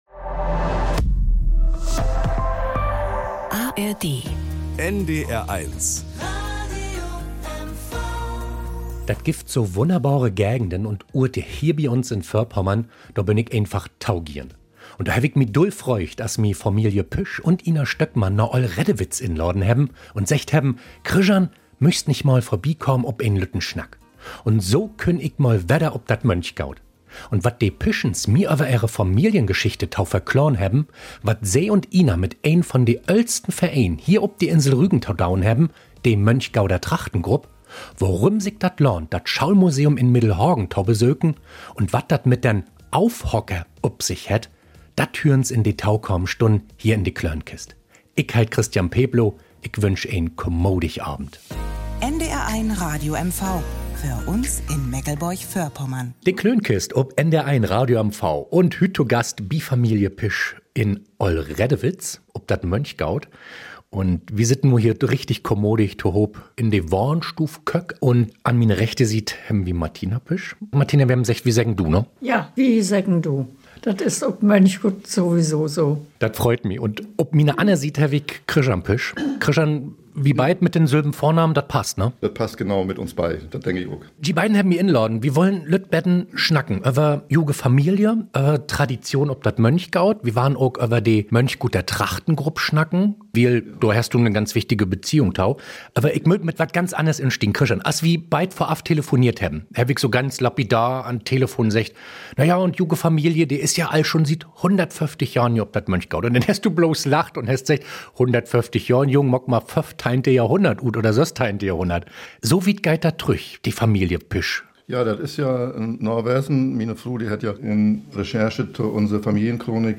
Nachrichten aus Mecklenburg-Vorpommern - 28.05.2024